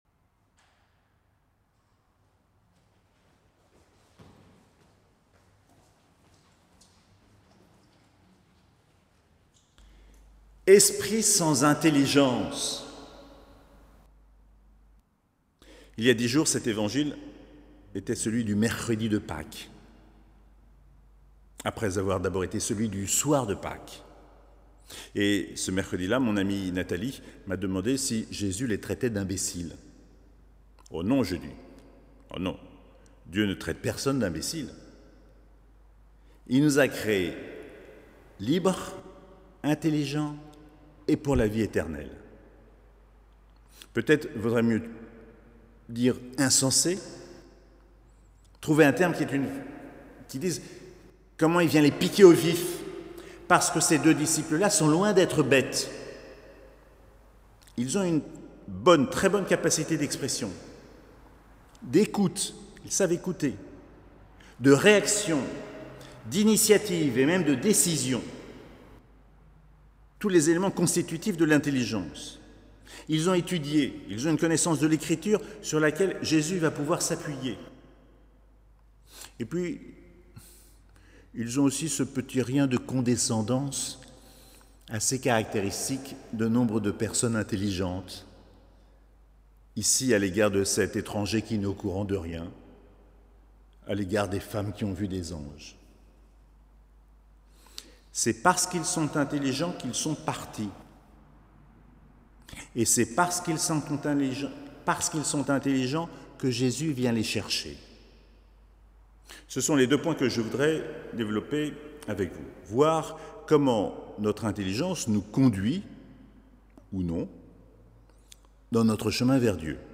3ème dimanche de Pâques - 26 avril 2020